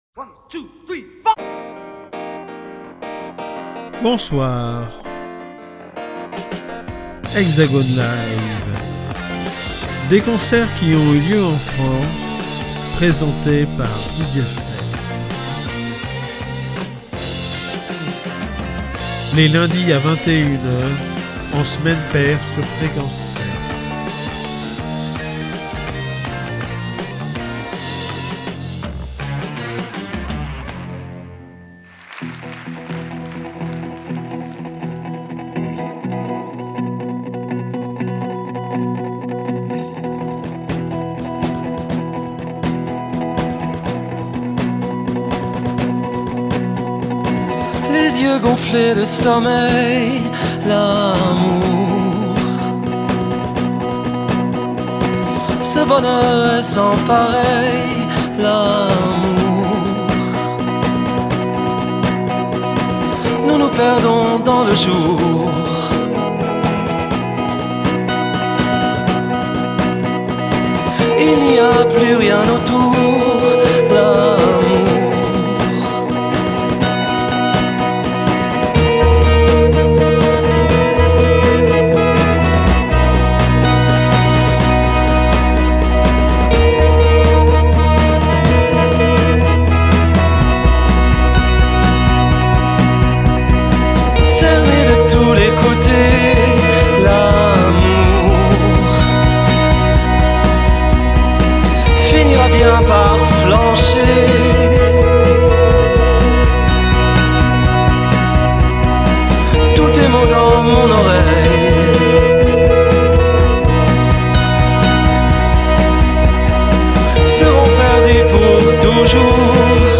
un concert